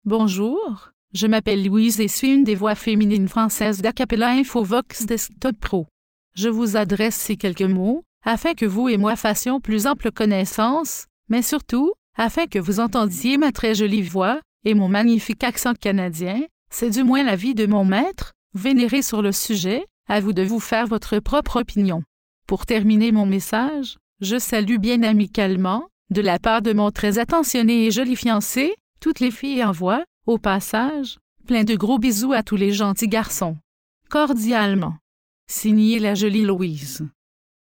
Texte de démonstration lu par Louise, voix féminine française canadienne d'Acapela Infovox Desktop Pro
Écouter la démonstration de Louise, voix féminine française canadienne d'Acapela Infovox Desktop Pro